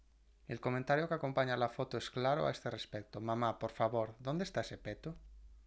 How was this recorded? Nexdata/Spanish_Speech_Data_by_Mobile_Phone at main